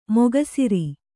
♪ moga siri